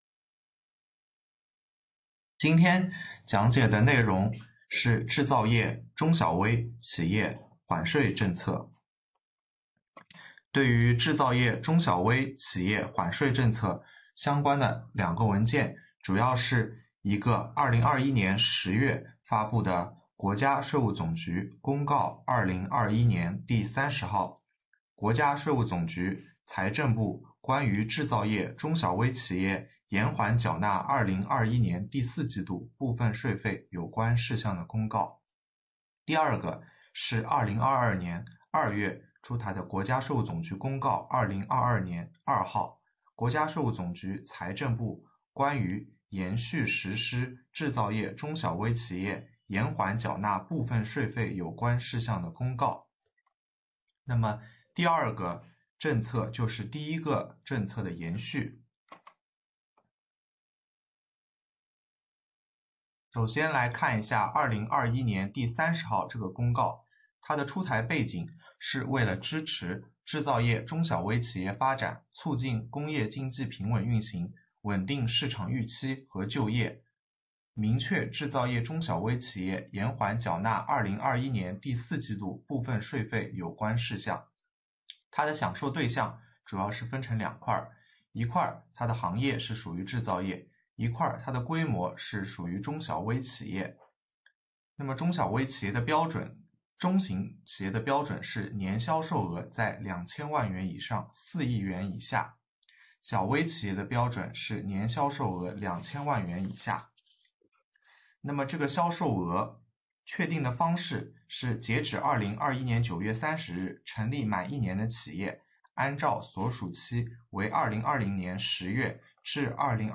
主讲人：奉贤区税务局